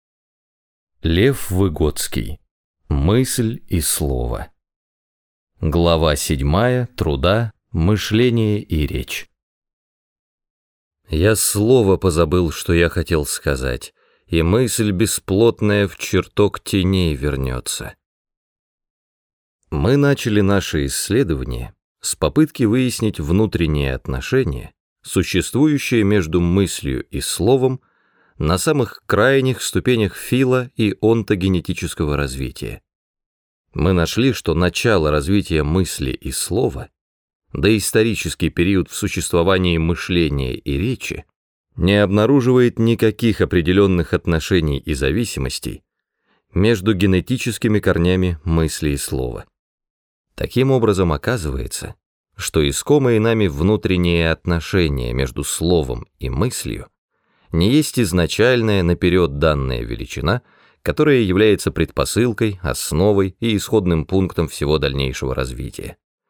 Раздел: Аудиокниги